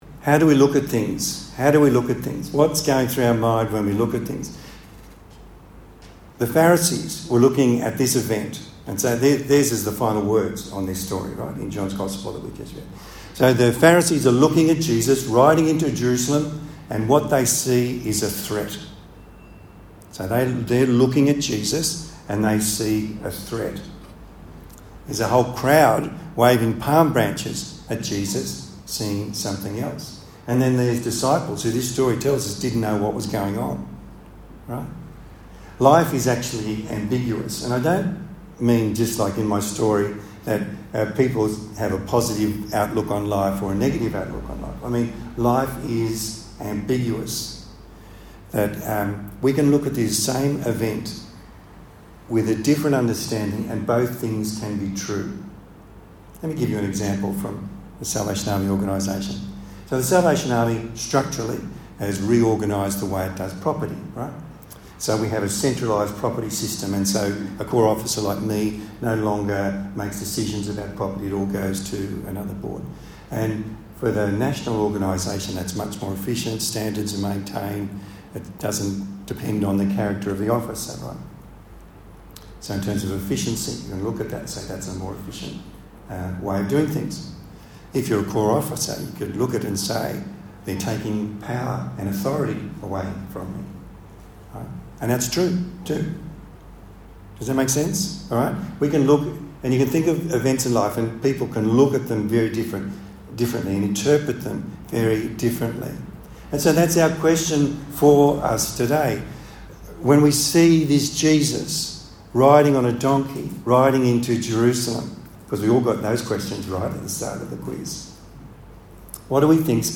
Palm Sunday Sermon